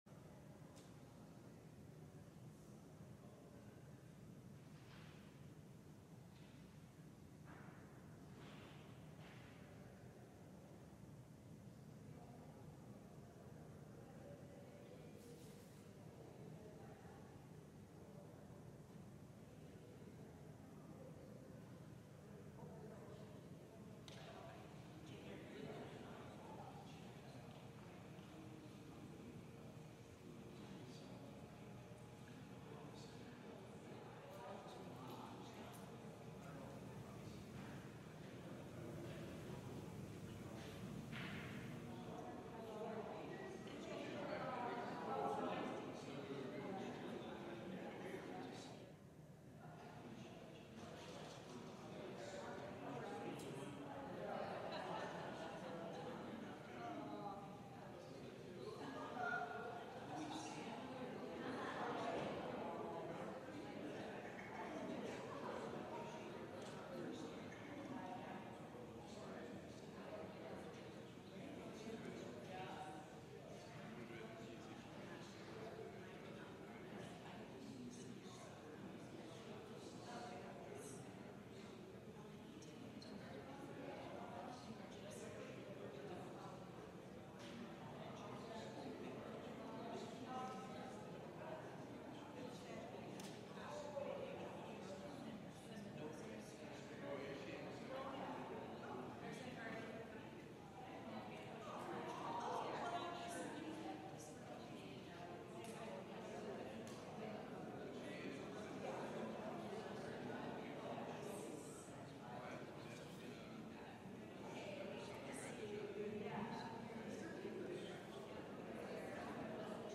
LIVE Evening Worship Service - The Lamp of God
Congregational singing—of both traditional hymns and newer ones—is typically supported by our pipe organ.